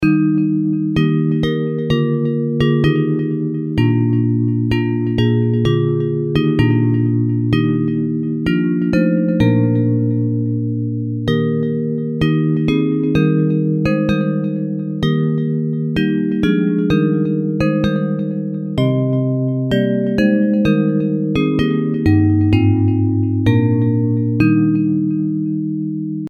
Hymns of praise
Bells Version